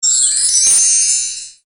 主持点开始游戏音效.wav